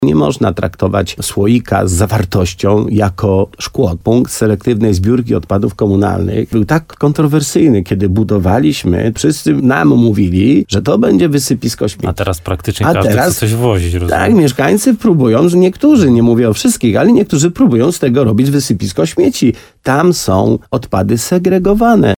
Wójt gminy Korzenna podkreśla, że w tej kwestii cały czas potrzebna jest edukacja.